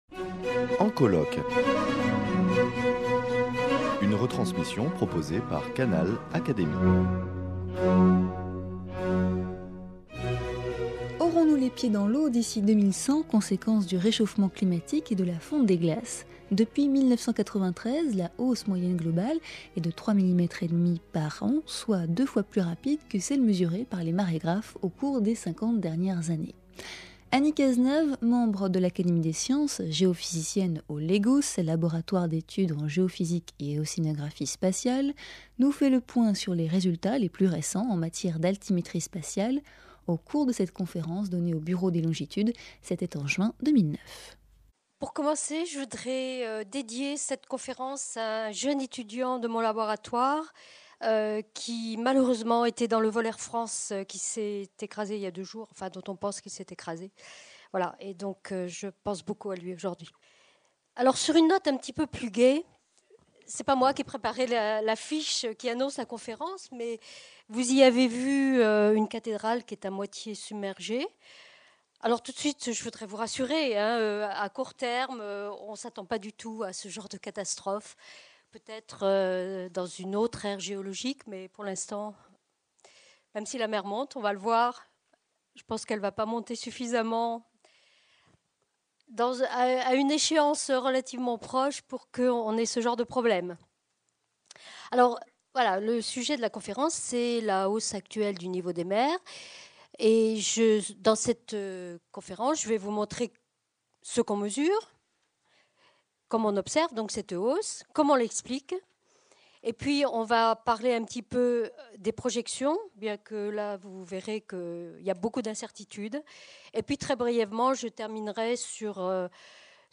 Anny Cazenave fait le point sur les résultats les plus récents, dans cette conférence donnée au Bureau des longitudes en juin 2009.